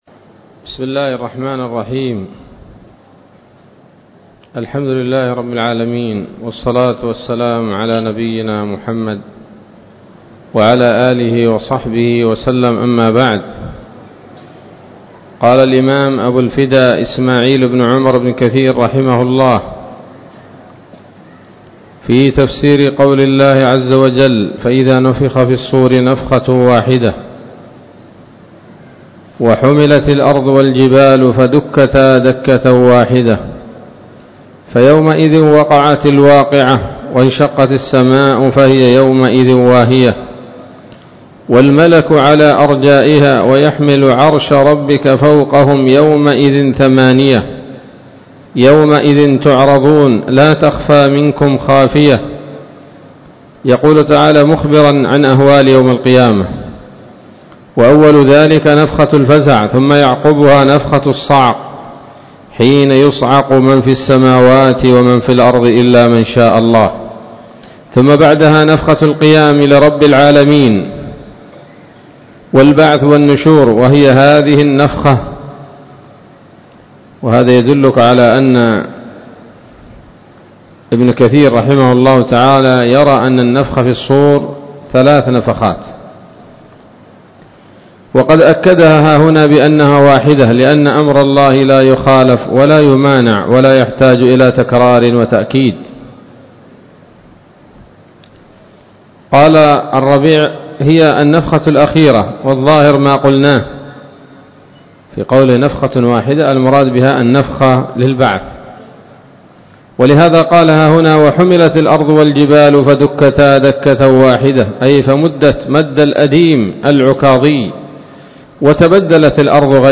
الدرس الثاني من سورة الحاقة من تفسير ابن كثير رحمه الله تعالى